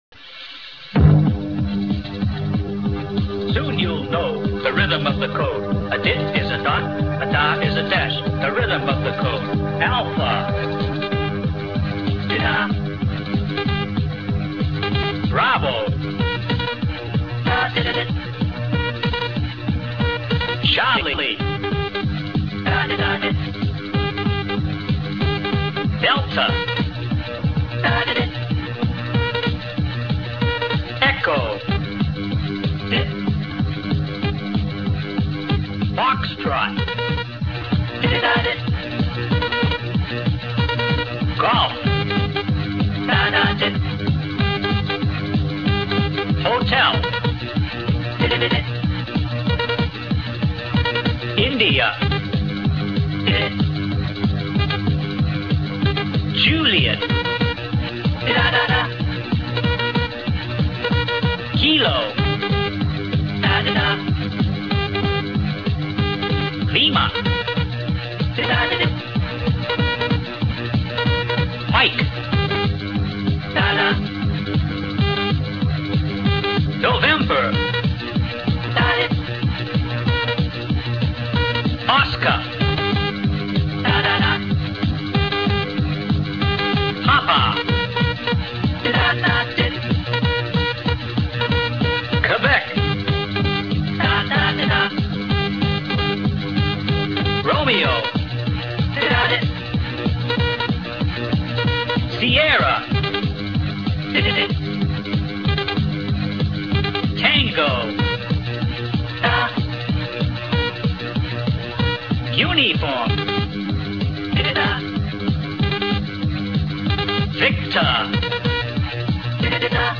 Telegrafia
Una linea � eguale a tre punti; Lo spazio tra segnali della stessa lettera � eguale a un punto; Lo spazio tra due lettere � eguale a tre punti (cio� una linea); Lo spazio tra due parole � eguale a 7 punti.